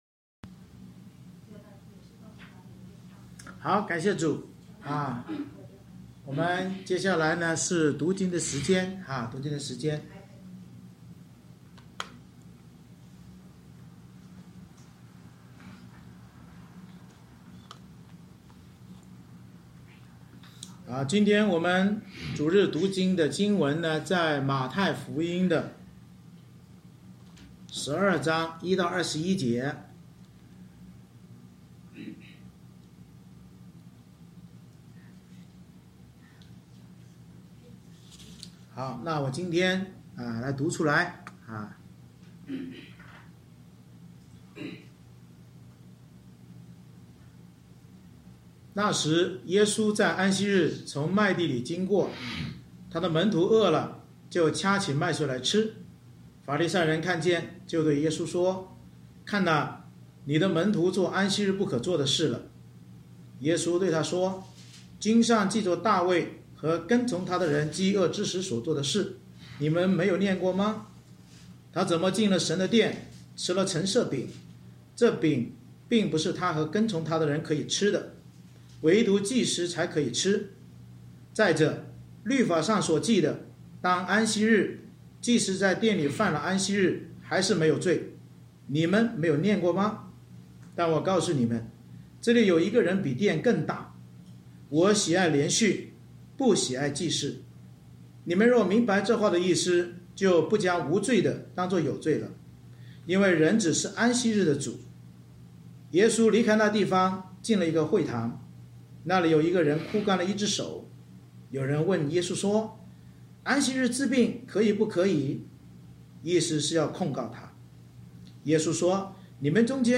马太福音12:1-21 Service Type: 主日崇拜 耶稣打破安息日不作事和不行善的教条，因祂是安息日之主并应验先知预言，教导我们要效法基督施行公义怜悯，生命才不会枯干而影响万民。